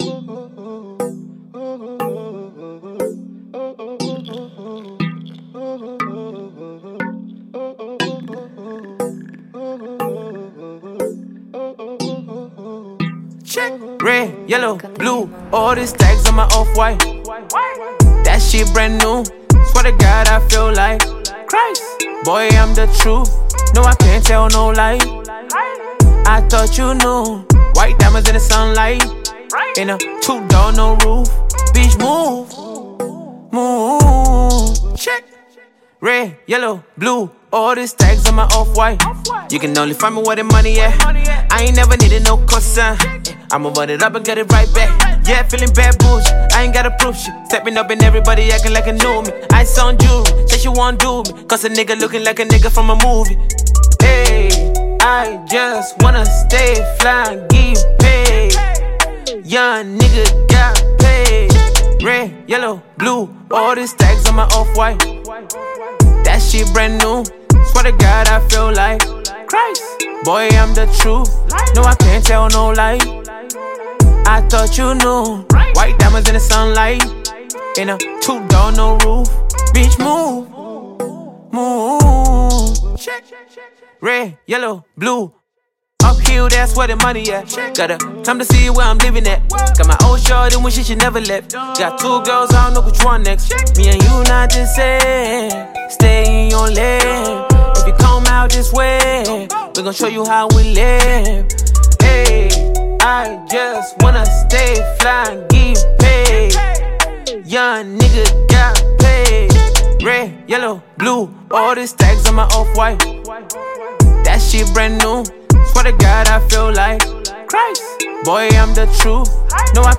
Nigerian trapper, singer and songwriter